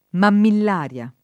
mammillaria
vai all'elenco alfabetico delle voci ingrandisci il carattere 100% rimpicciolisci il carattere stampa invia tramite posta elettronica codividi su Facebook mammillaria [ mammill # r L a ] (meno com. mamillaria [ mamill # r L a ]) s. f. (bot.)